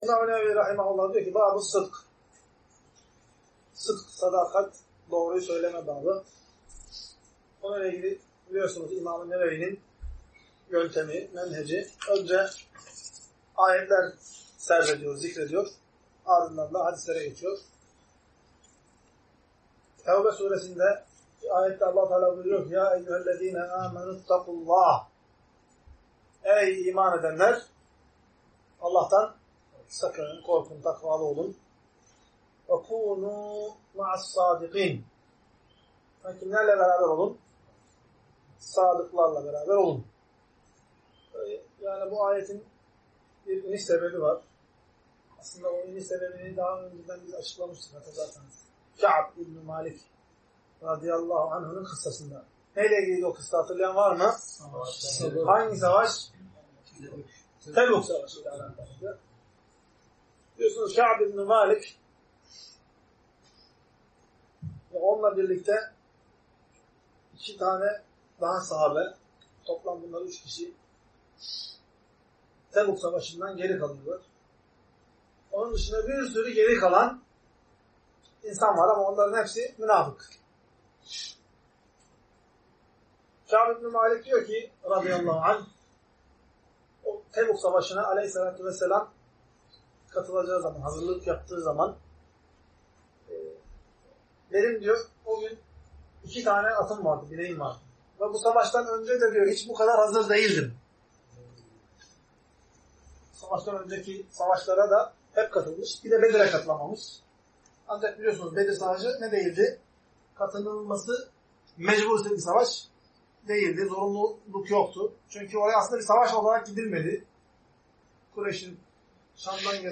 Ders - 4.